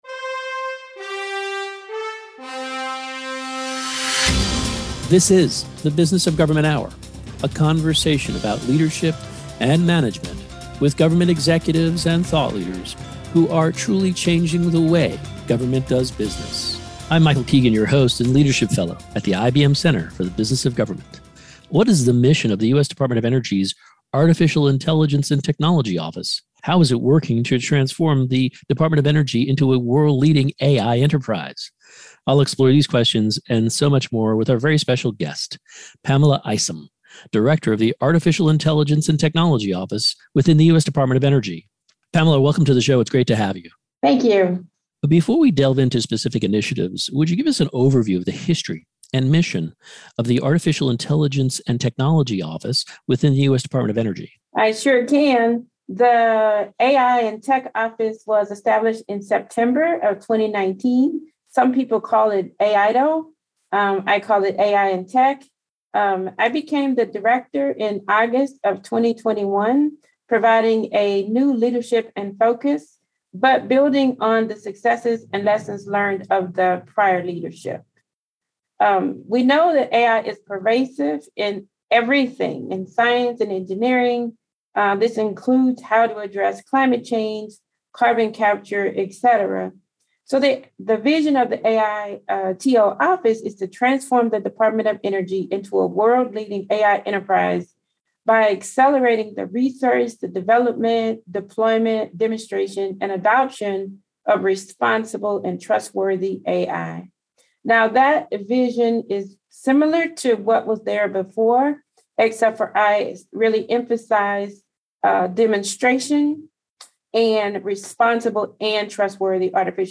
Radio Hour